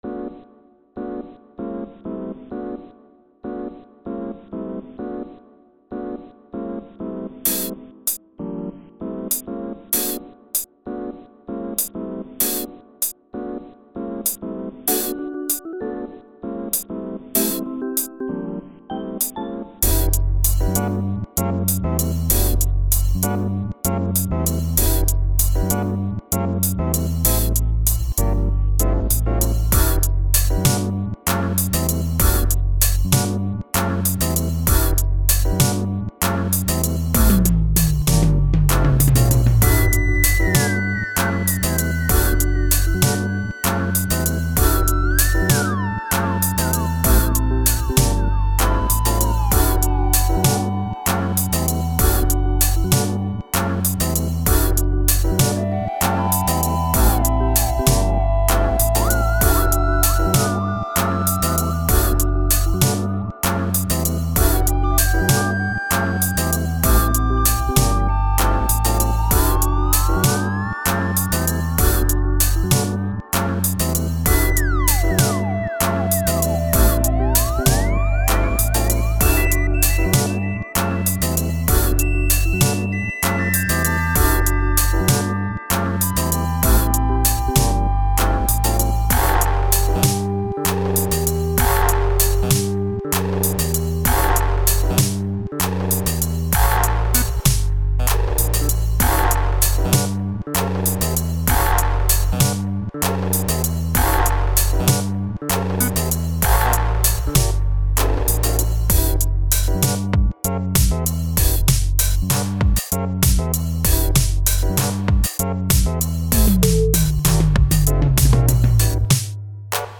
These were all written in music making software.
My more or less traditional Christmas song.